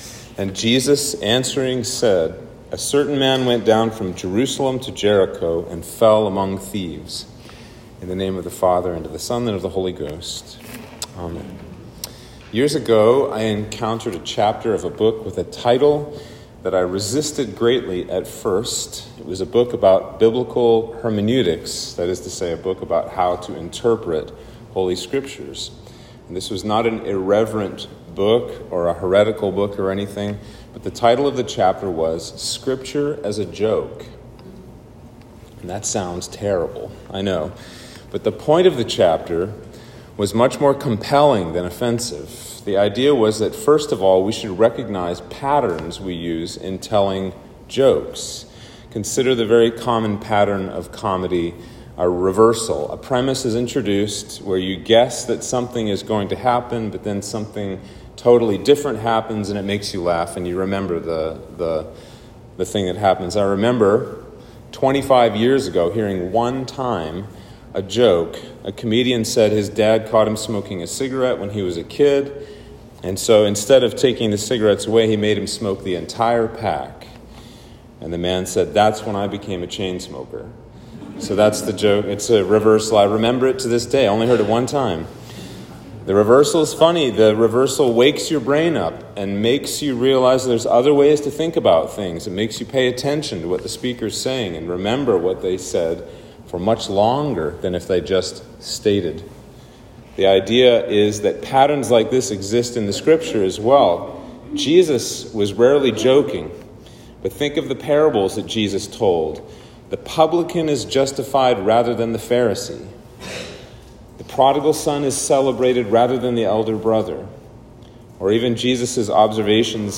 Sermon for Trinity 13